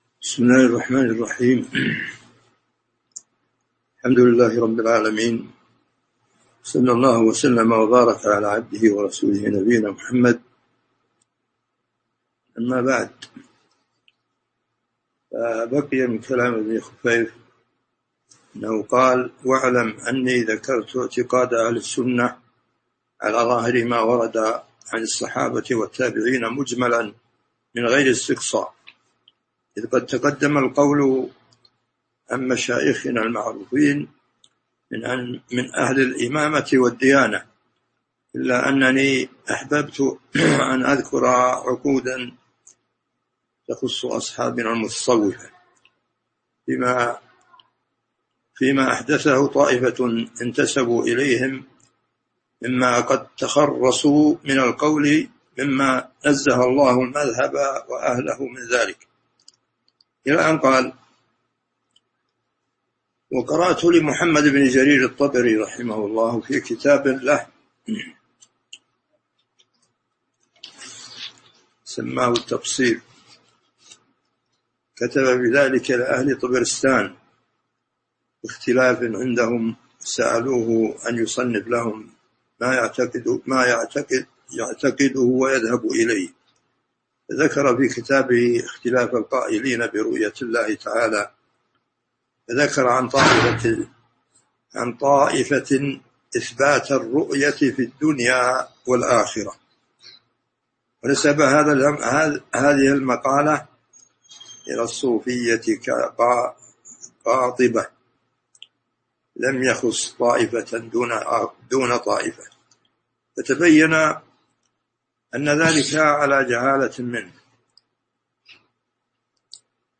تاريخ النشر ٧ ذو القعدة ١٤٤٢ هـ المكان: المسجد النبوي الشيخ